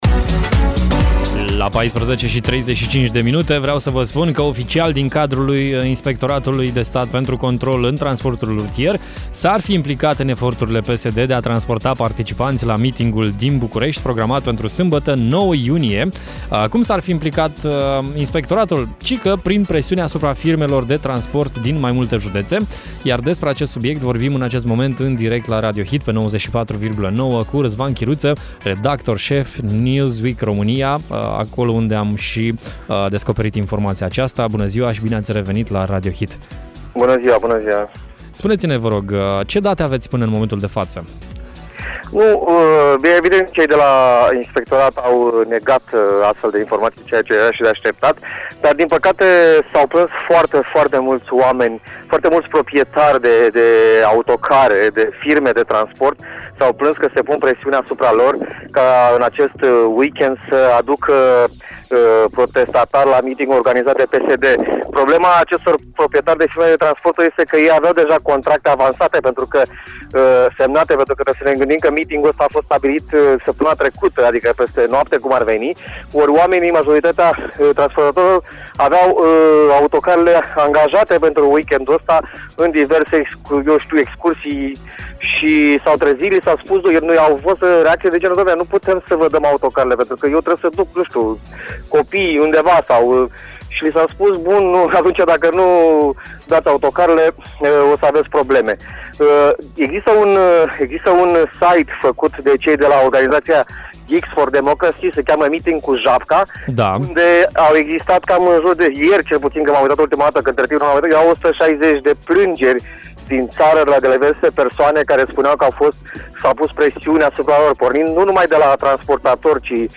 Conform Newsweek România, PSD-ul se pare că face presiuni prin instituțiile statului asupra firmelor de transport din mai multe județe. Mai multe detalii am aflat în direct la Radio Hit